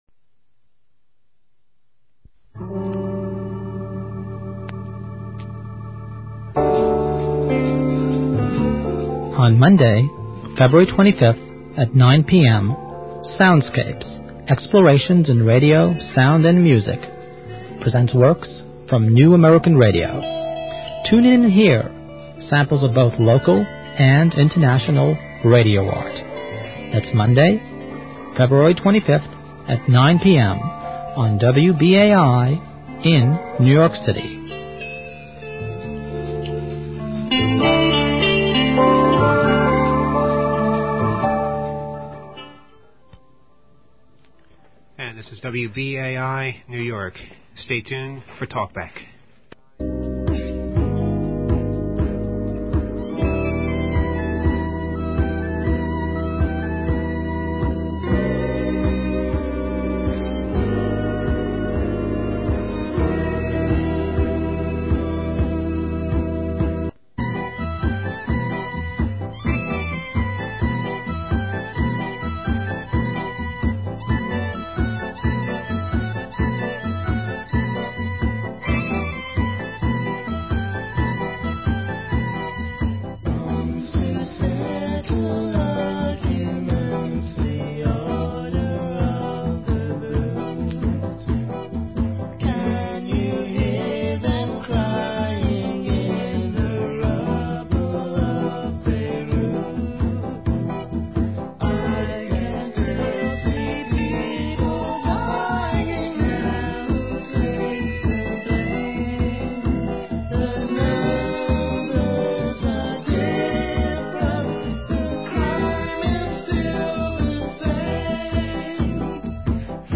a demonstration of numbers stations in different languages, examples of tone codes